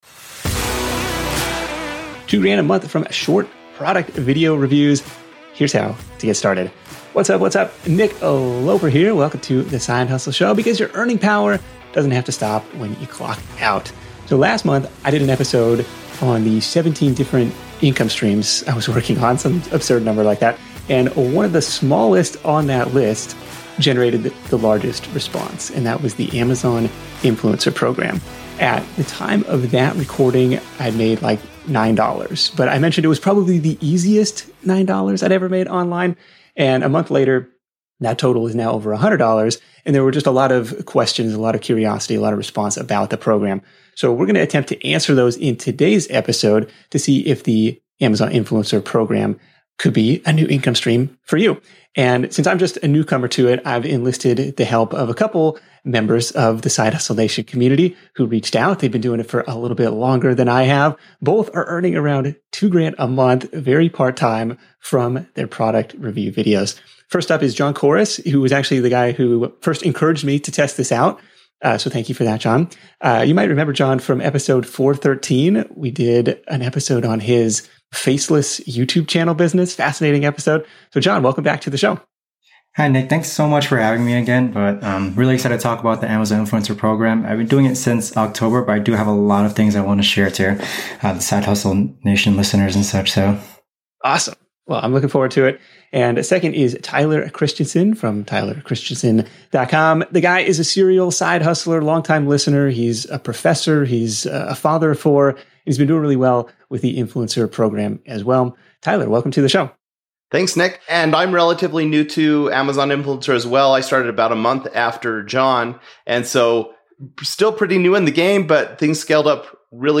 Side Hustle Show interview